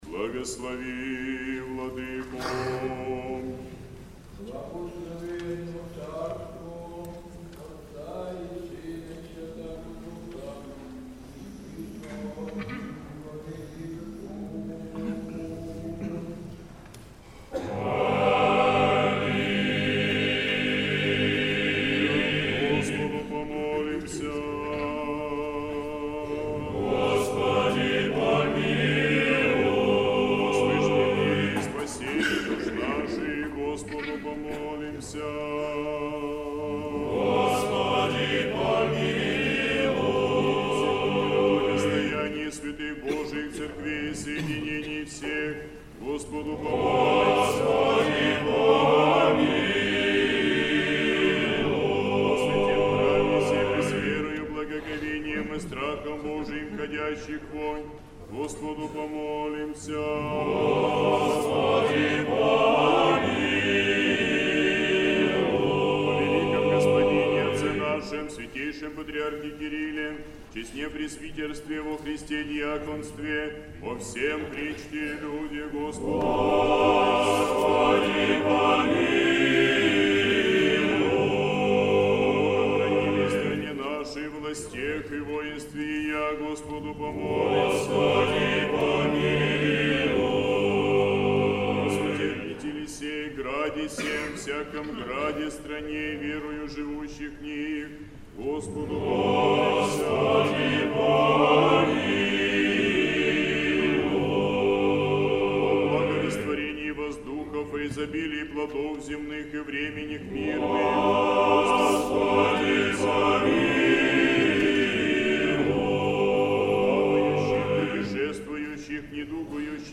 Божественная литургия. Хор Сретенского монастыря.
Божественная литургия в Сретенском монастыре в Неделю 25-ю по Пятидесятнице
Крипта собора Сретения Владимирской иконы Божией Матери.